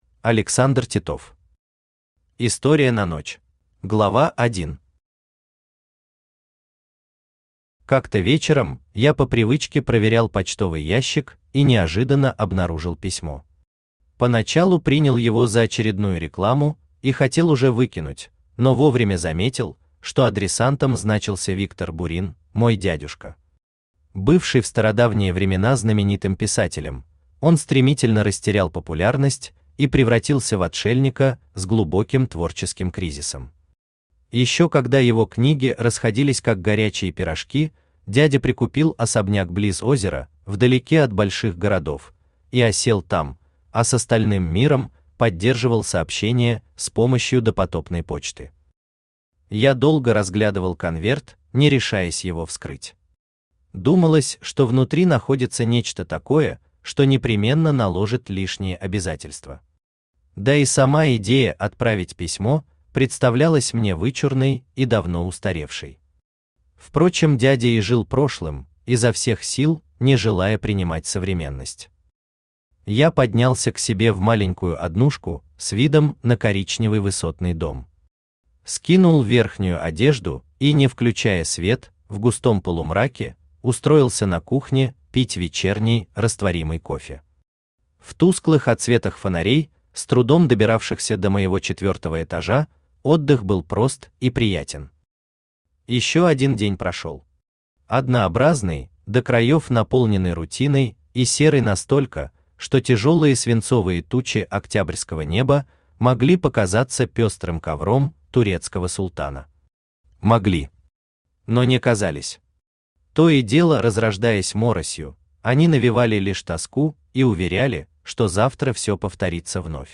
Аудиокнига История на ночь | Библиотека аудиокниг
Aудиокнига История на ночь Автор Александр Титов Читает аудиокнигу Авточтец ЛитРес.